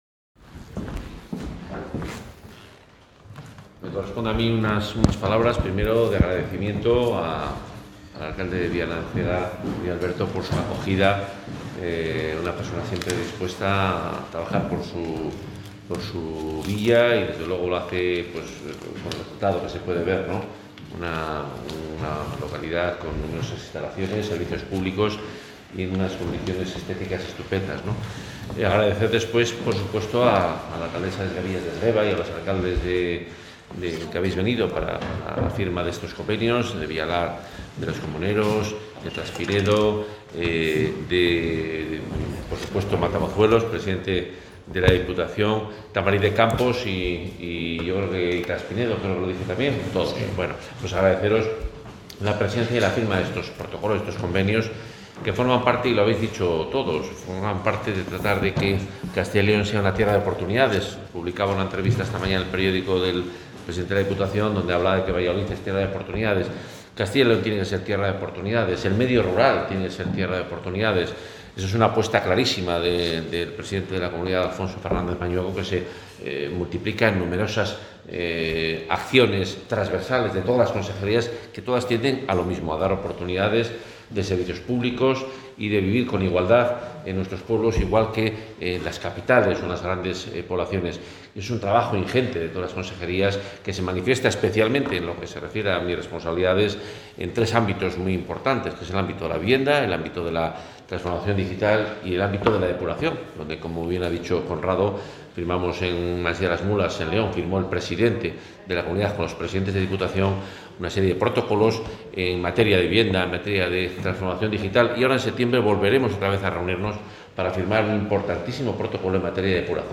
Intervención del consejero de Fomento y Medio Ambiente.